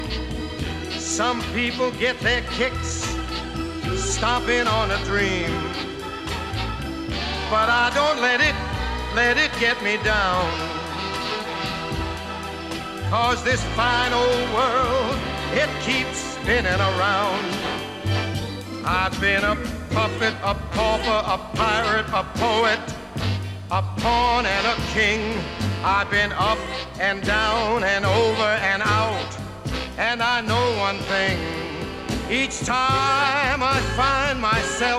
# Vocal Jazz